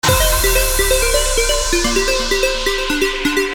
• Качество: 320, Stereo
красивые
без слов